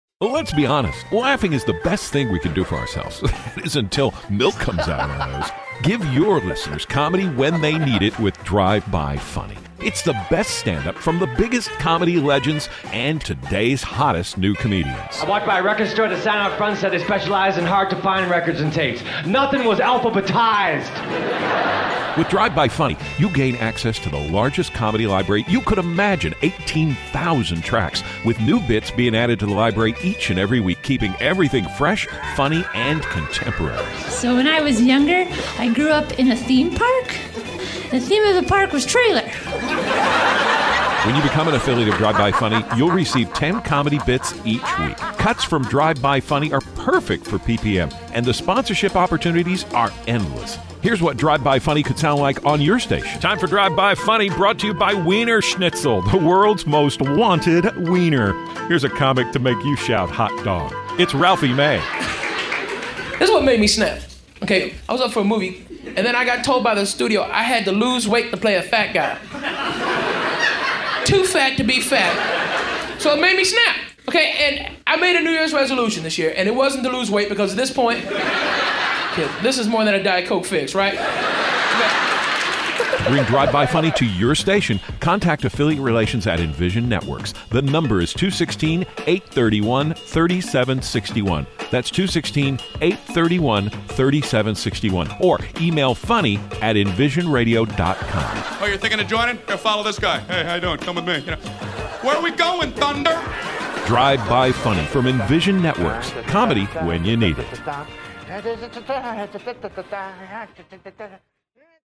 Comedy Cuts That Fit Your Format!